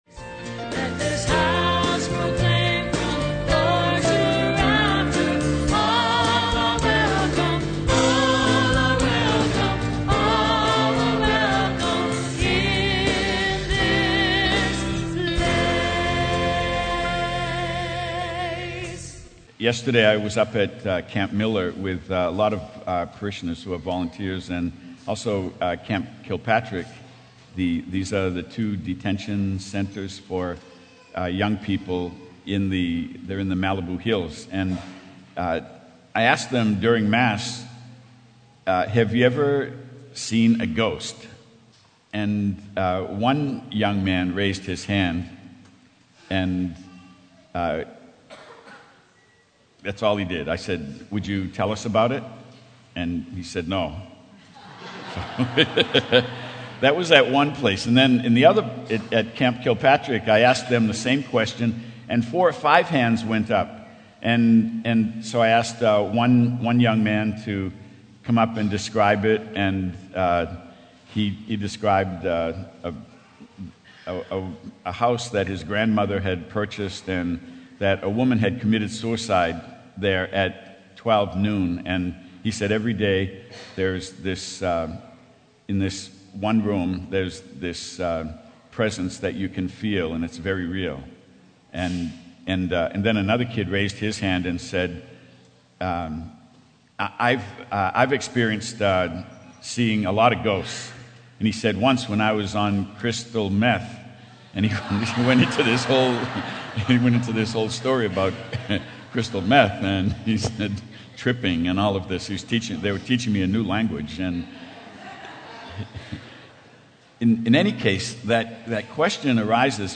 Homily - 4/22/12 - 3rd Sunday Easter